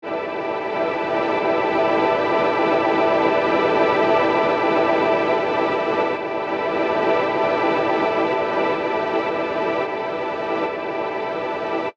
Download Edm sound effect for free.
Edm